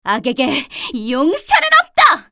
cleric_f_voc_skill_divineshield.mp3